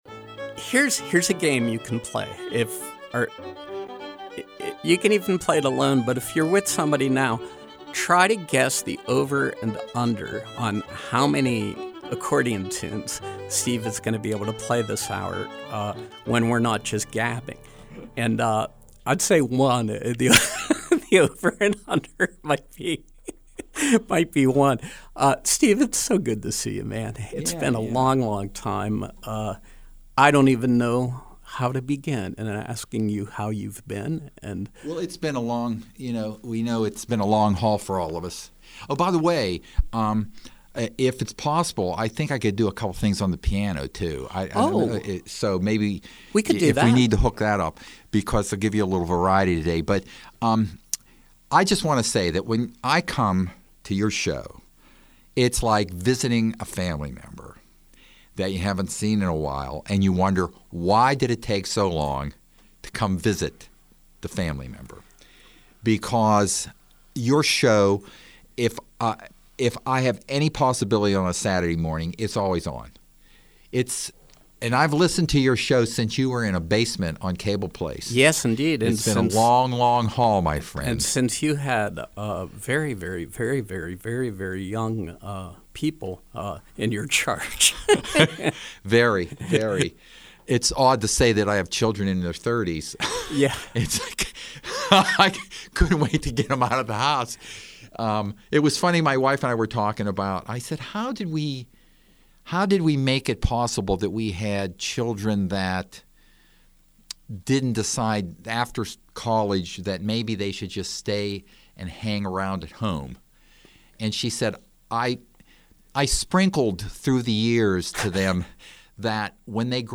Live Music
Accordion music and conversation
Performance Live Music